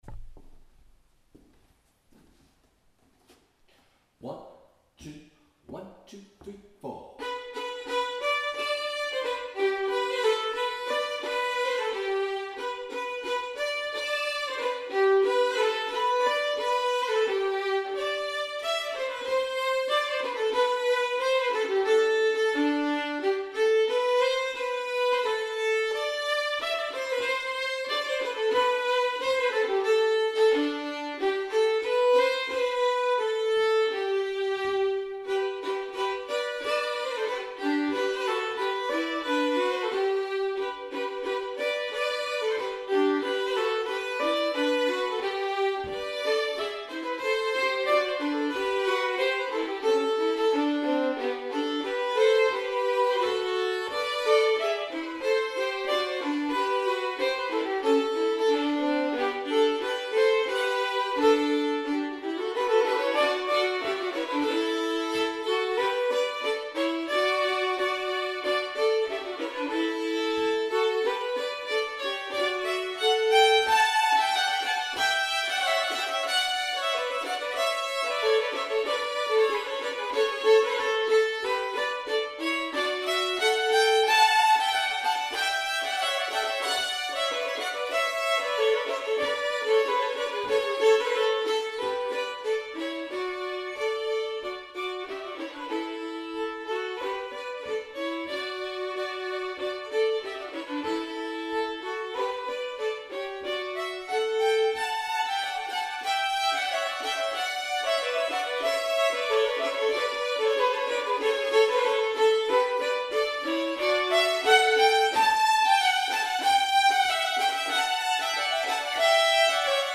TFA Carols 2 – Brisk